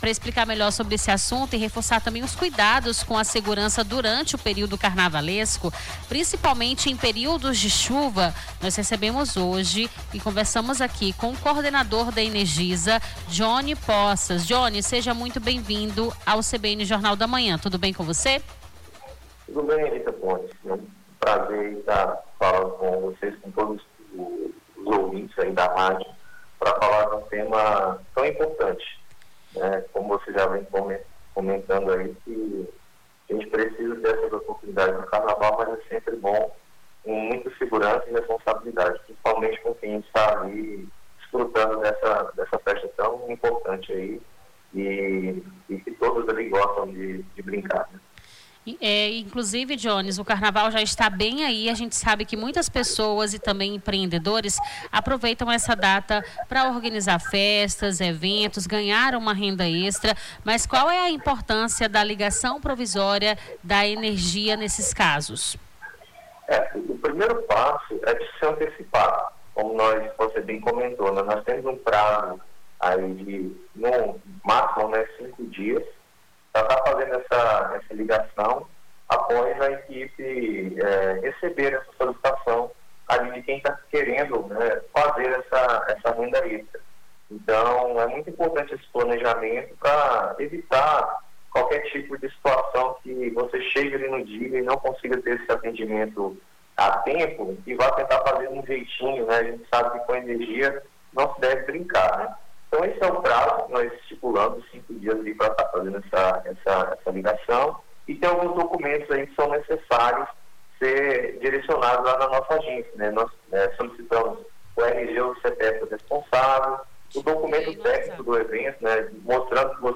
AO VIVO: Confira a Programação